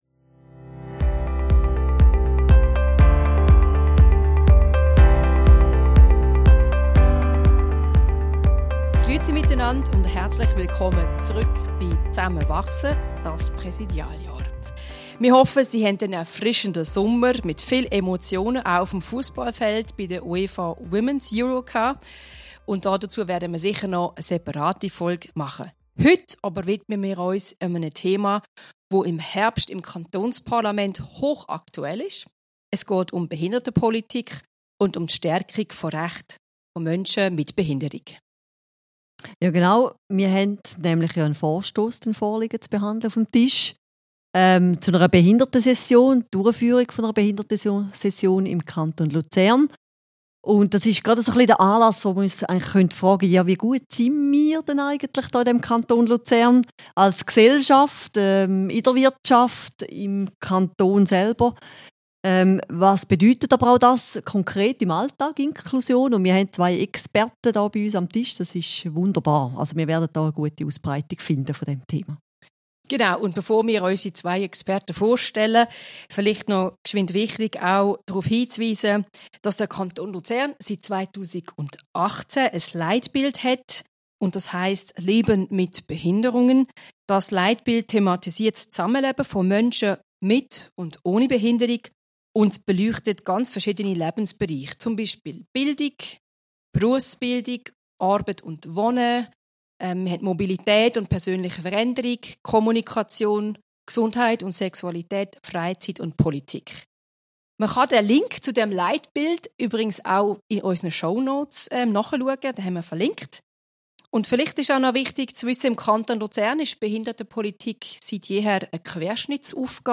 Folge 2: Behindertenpolitik und Inklusion im Kanton Luzern Wie inklusiv ist der Kanton Luzern wirklich? In dieser Folge diskutieren wir mit spannenden Gästen über Chancen, Hürden und persönliche Erfahrungen rund um Behindertenpolitik und Inklusion.